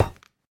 Minecraft Version Minecraft Version 25w18a Latest Release | Latest Snapshot 25w18a / assets / minecraft / sounds / block / netherite / step5.ogg Compare With Compare With Latest Release | Latest Snapshot
step5.ogg